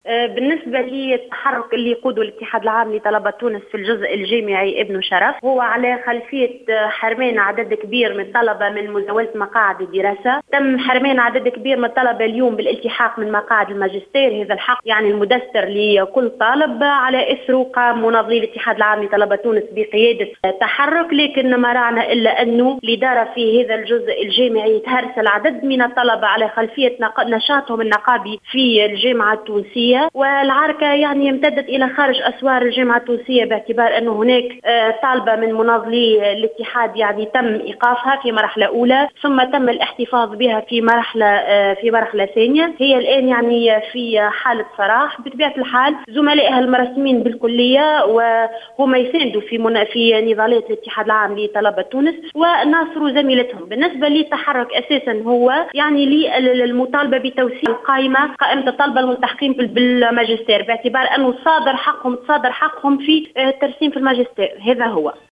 تصريح للجوهرة اف ام